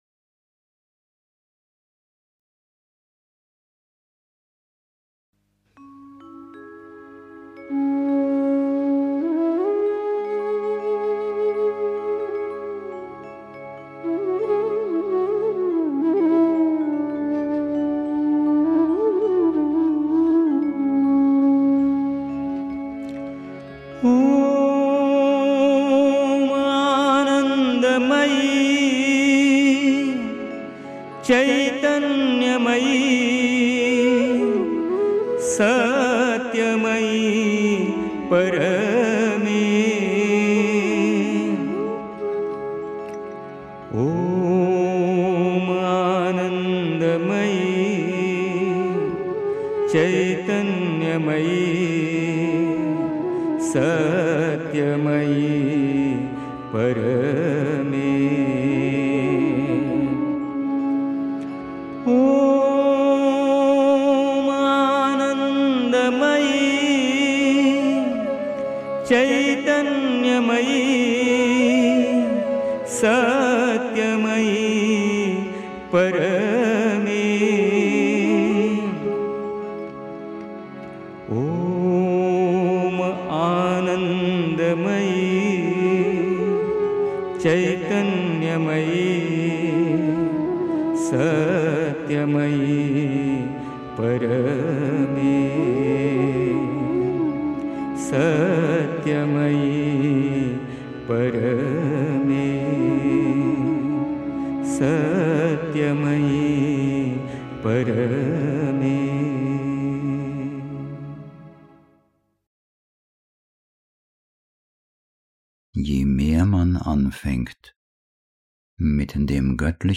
1. Einstimmung mit Musik. 2. Die quälende Empfindung des irdischen Lebens der Falschheit (Die Mutter, White Roses, 13 November 1962) 3. Zwölf Minuten Stille.